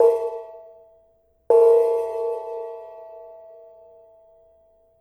Bonang Barung Pl 6l of Kyai Parijata in real time & at one-third speed